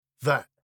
• that（その）：ðæt →  vet
🇬🇧発音：that（その）